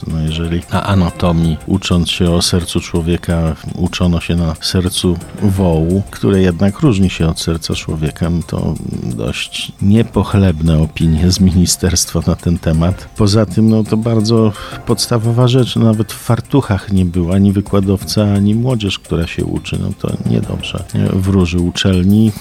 – I ta kontrola wiceministra wypadła niezbyt pozytywnie – powiedział gość Mocnej Rozmowy, poseł Marek Suski.